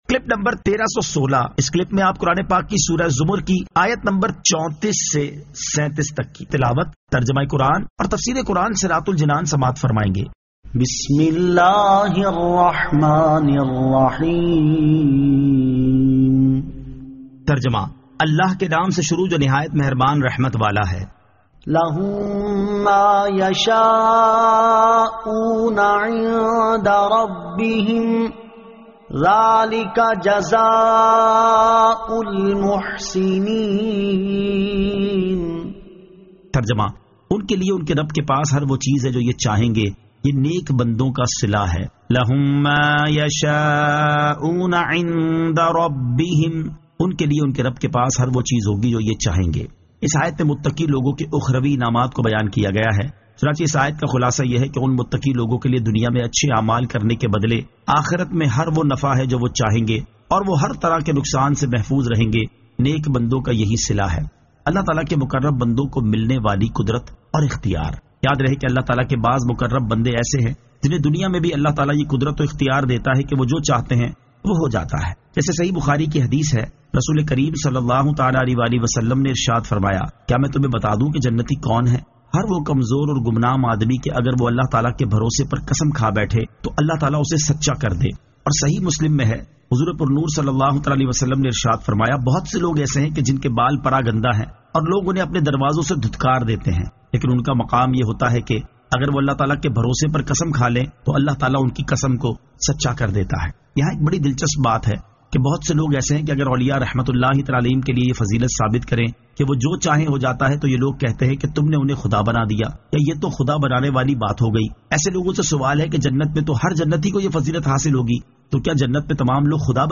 Surah Az-Zamar 34 To 37 Tilawat , Tarjama , Tafseer